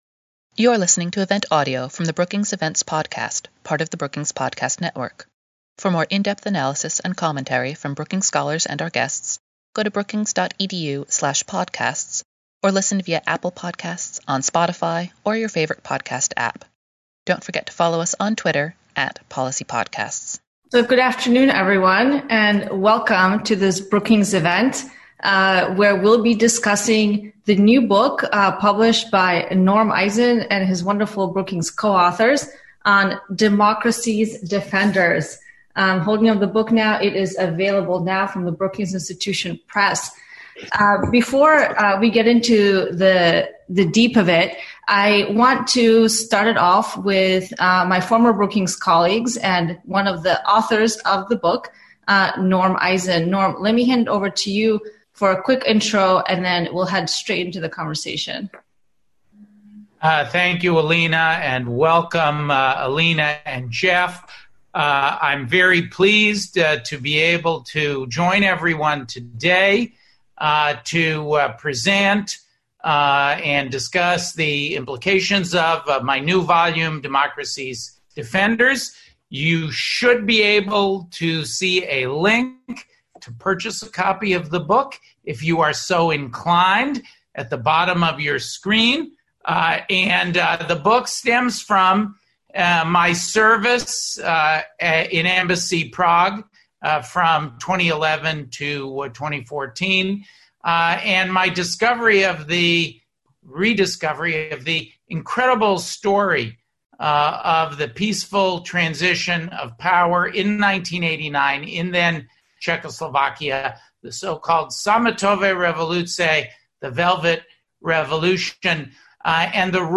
Webinar: Democracy’s defenders — American diplomacy in the age of COVID-19 | Brookings